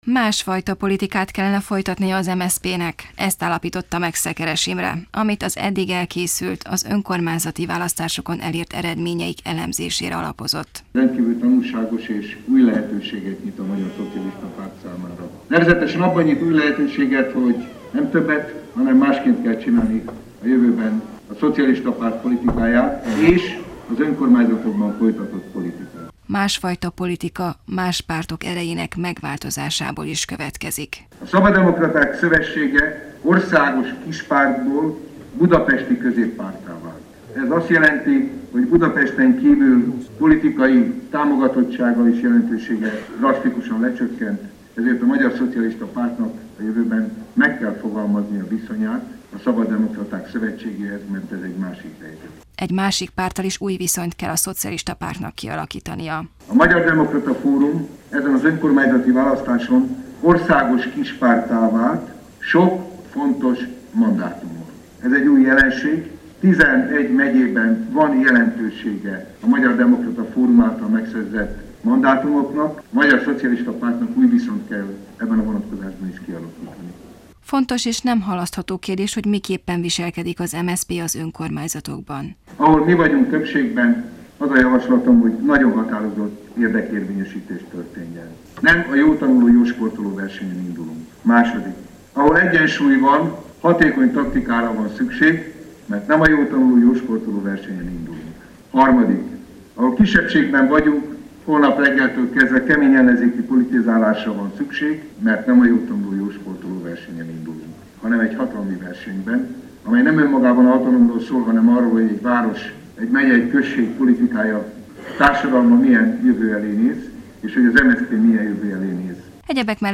Szekeres Imrének, az MSZP elnökhelyettesének titokban felvett beszédét tette közzé hétfőn a magyar közszolgálati rádió.
Az MSZP Országos Választmányának szombaton, zárt ajtók mögött tartott ülésén a szocialista politikus az önkormányzati választás eredményét elemezte, abból vont le tanulságokat.